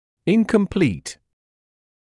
[ˌɪnkəm’pliːt][ˌинкэм’плиːт]неполный; незавершённый; дефективный